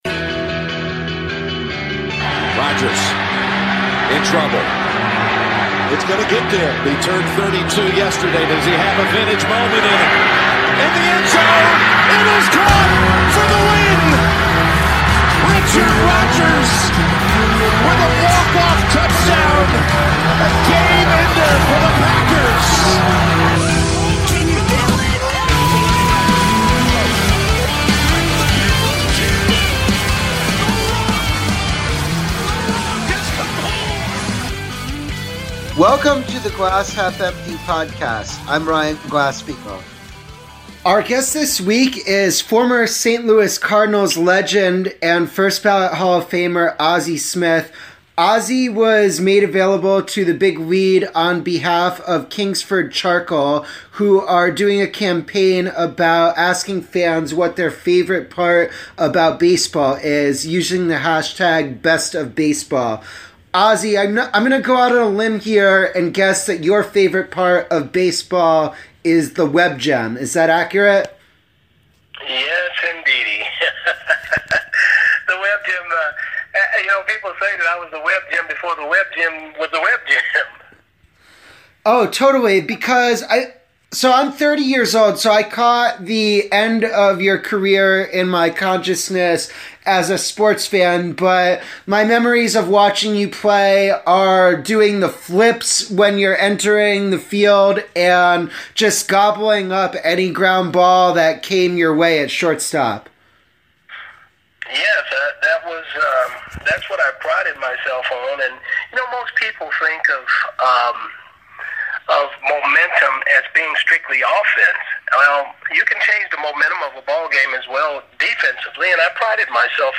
A conversation with the MLB Hall of Famer.